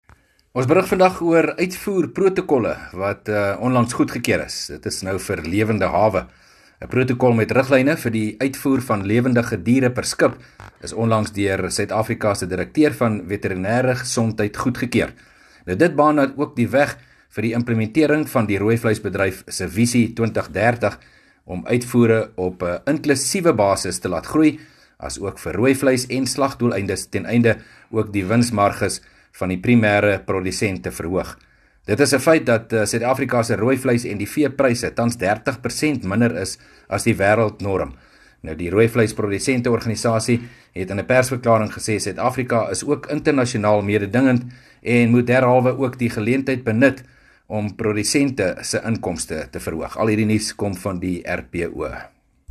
30 Jul PM berig oor die protokol vir die uitvoer van lewendehawe wat onlangs aangepas is